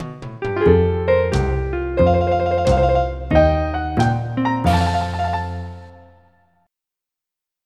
country2.mp3